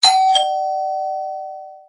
Index of /phonetones/unzipped/Pantech/Flex-P8010/alarms
Dingdong.ogg